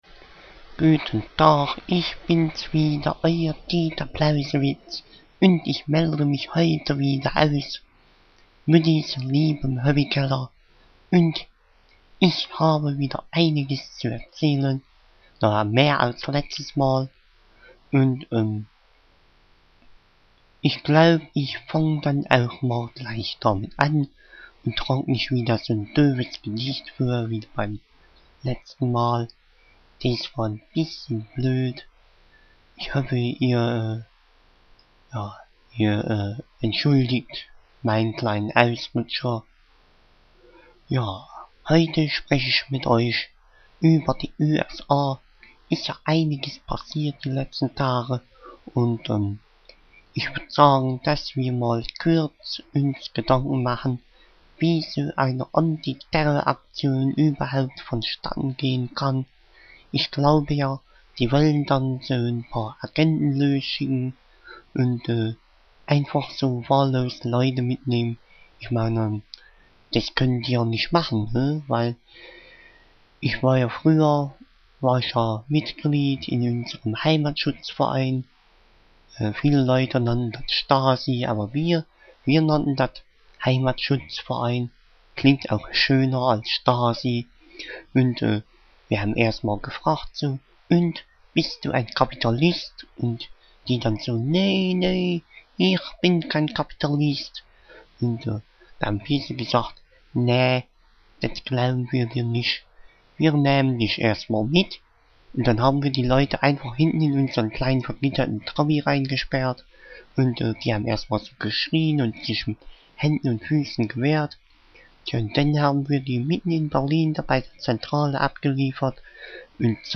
welcher daheim bei seiner Mutti im Hobbykeller in unregelmäßigen Abständen eine eigene Talksendung führt
dass seine Aussprache nicht nur durch seinen Akzent, sondern auch durch eine Nasalität ins beinahe unverständliche abrutscht.